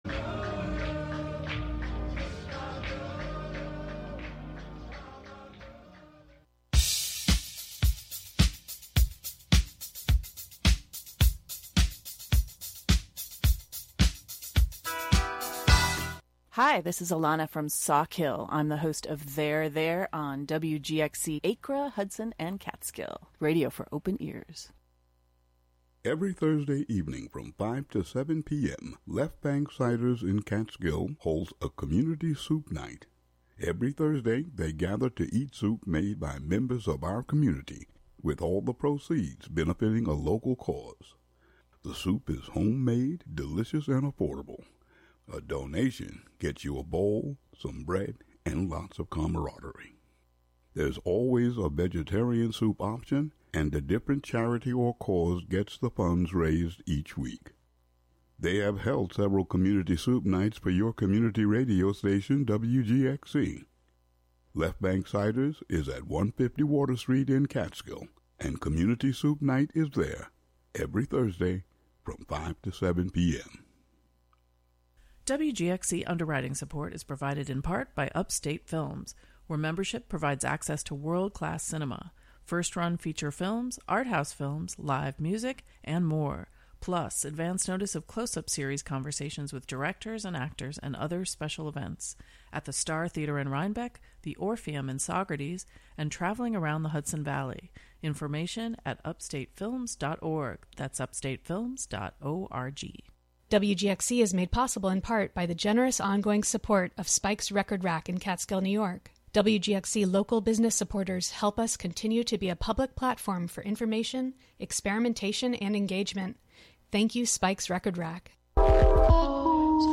Expect lively conversation and a playlist of great music to go with it.